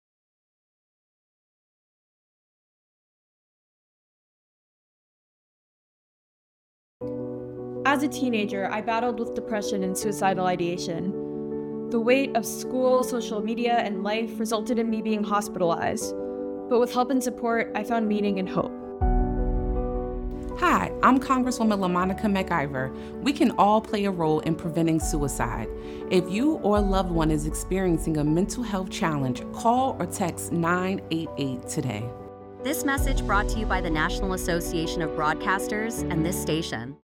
Representatives Herb Conaway (NJ-03), LaMonica McIver (NJ-10), and Rob Menendez (NJ-08) recorded radio and television PSAs covering a multitude of important subjects.